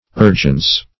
urgence - definition of urgence - synonyms, pronunciation, spelling from Free Dictionary Search Result for " urgence" : The Collaborative International Dictionary of English v.0.48: Urgence \Ur"gence\, n. Urgency.